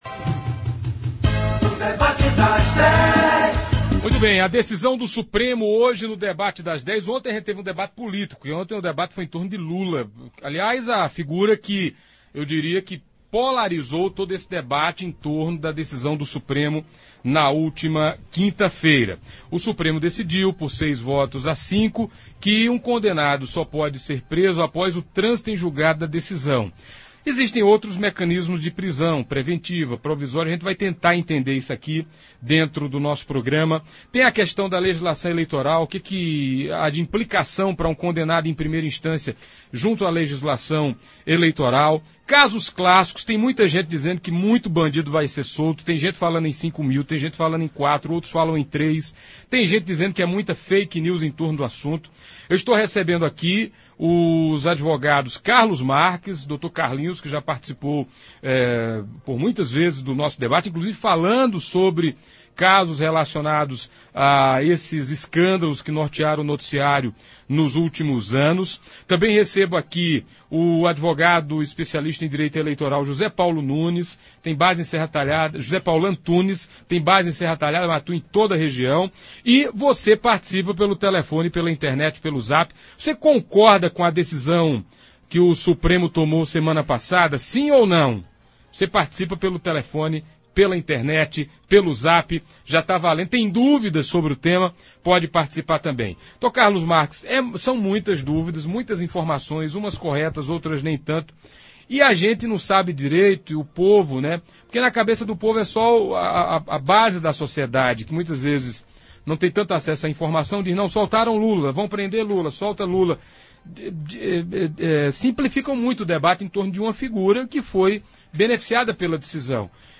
Ouça abaixo a íntegra do debate de hoje: Um fato que chamou a atenção durante o debate foram as posições dos dois advogados com relação a influência de grandes bancas de advogados junto a juízes e instituições.